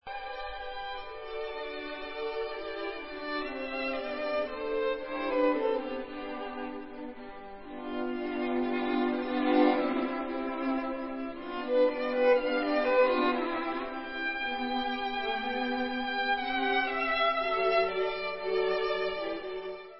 housle
Koncert pro housle a orchestr č. 1 D dur, op. 3:
Adagio maestoso